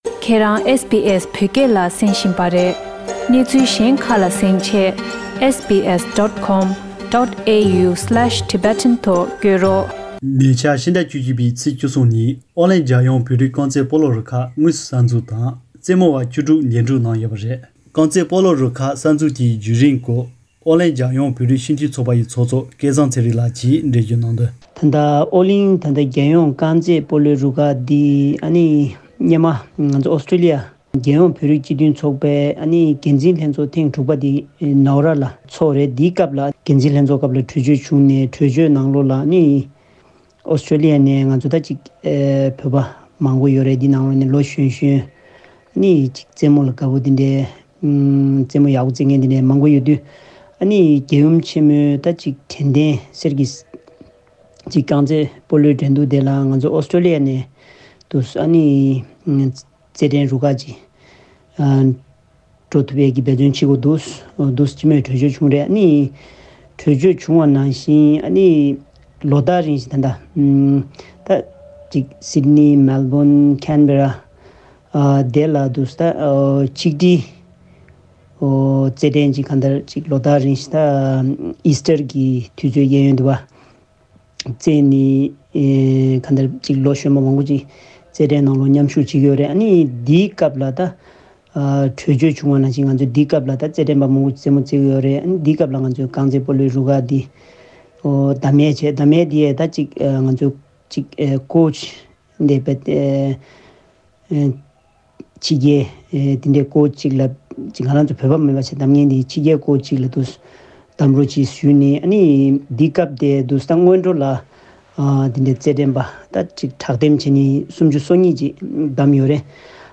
བཅར་འདྲི་ཞུས།